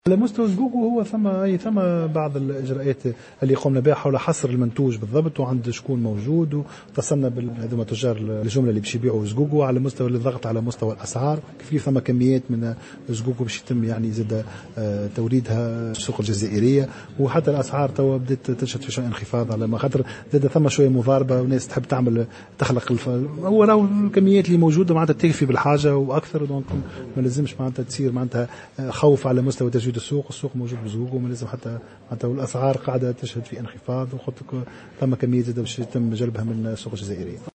وقال الباهي في تصريح لمراسلة الجوهرة اف ام، إن الوزارة اتخذت عديد الإجراءات للضغط على الأسعار، وتقوم بمراقبة مسالك التوزيع للحد من الاحتكار.